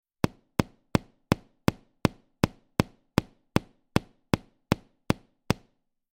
На этой странице собраны различные звуки постукивания пальцами: по столу, стеклу, дереву и другим поверхностям.
Стук длинных ногтей по деревянному бруску